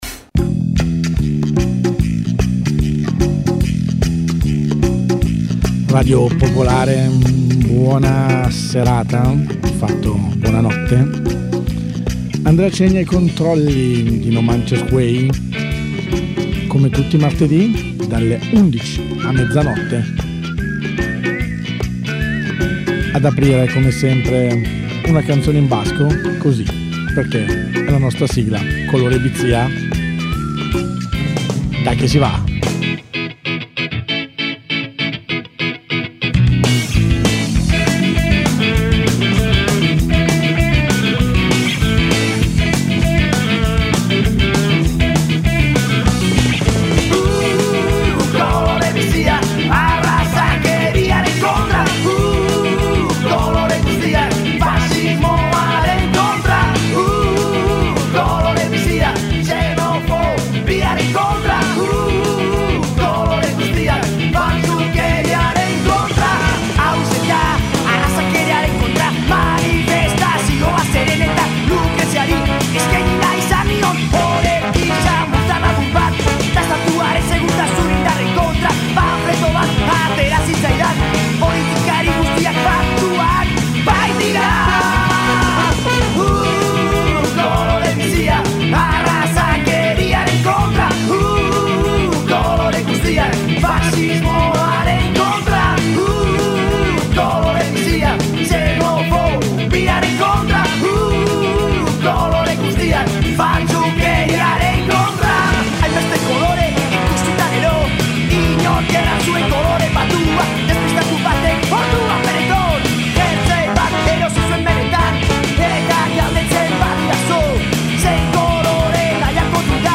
Un viaggio musicale dentro le culture latino americane.